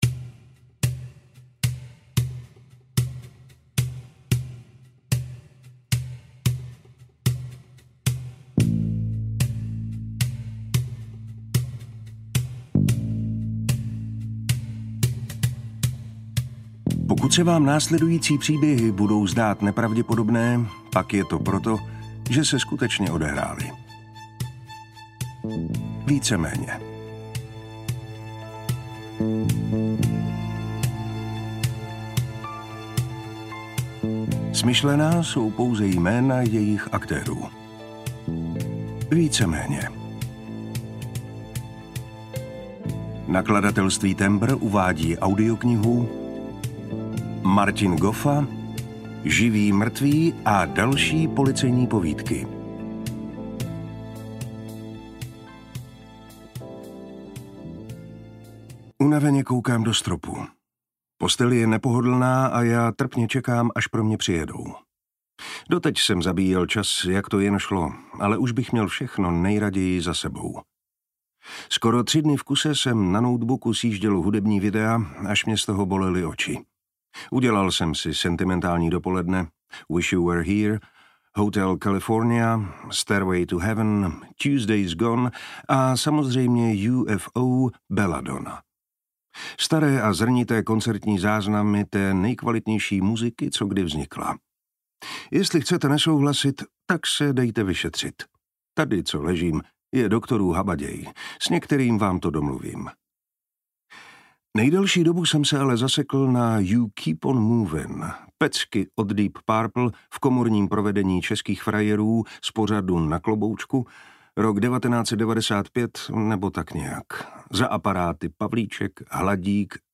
Živý mrtvý a další policejní povídky audiokniha
Ukázka z knihy